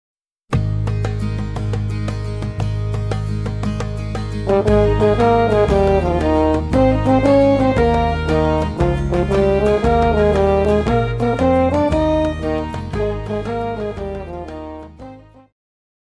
Pop
French Horn
Band
Instrumental
Only backing